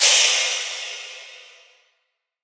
Gamer World Crash 1.wav